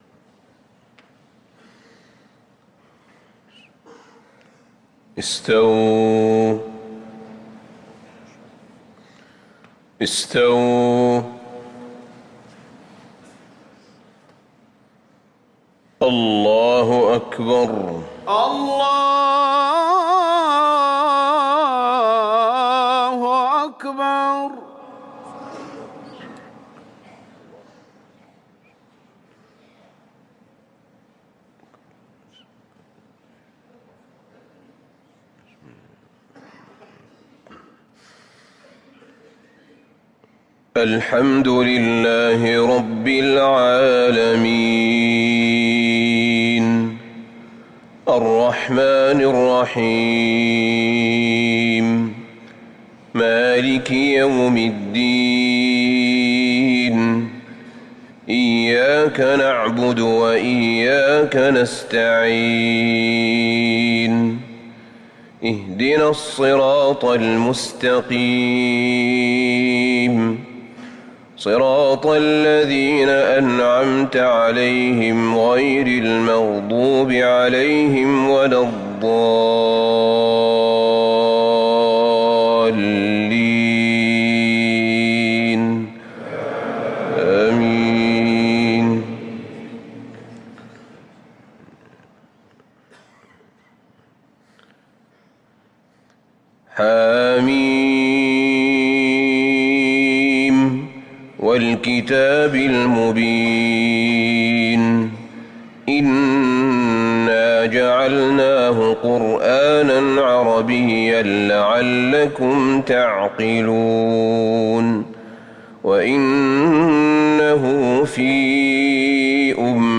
صلاة الفجر للقارئ أحمد بن طالب حميد 14 ربيع الآخر 1445 هـ